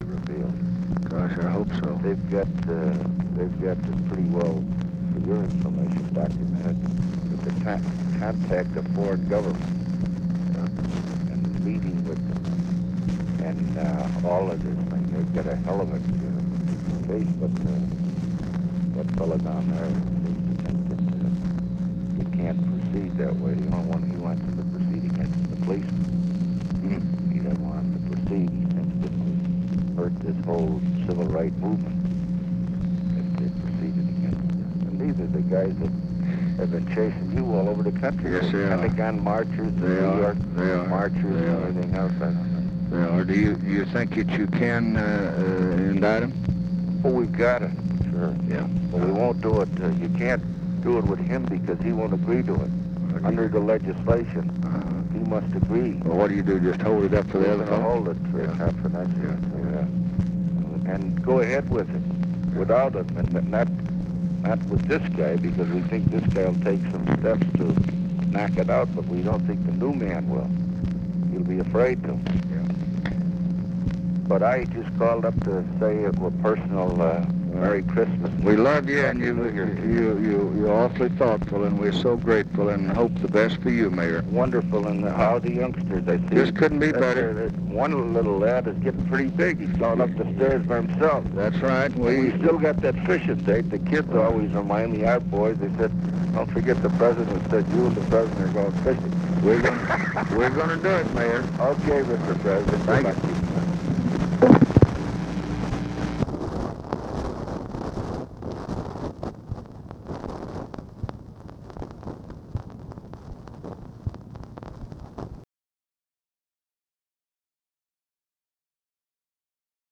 Conversation with RICHARD DALEY, December 26, 1968
Secret White House Tapes